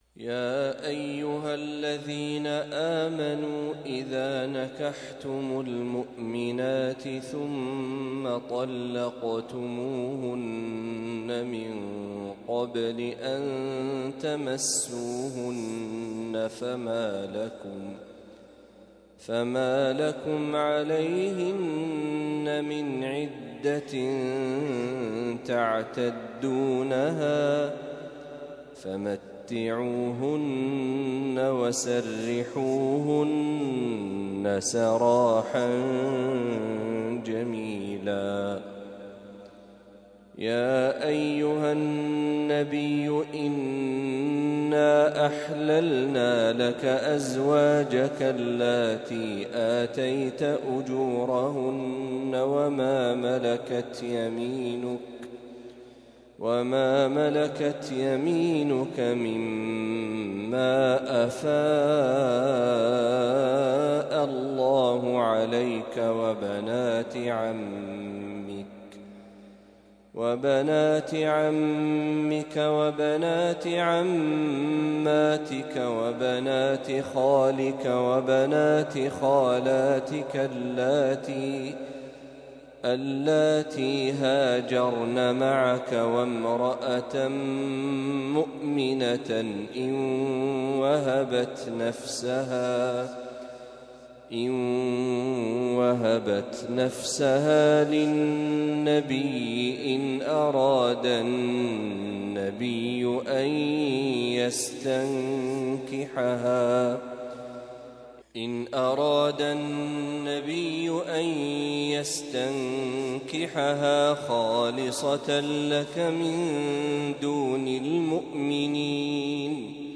ما تيسر من سورة الأحزاب | فجر الخميس ٢ ربيع الأول ١٤٤٦هـ > 1446هـ > تلاوات الشيخ محمد برهجي > المزيد - تلاوات الحرمين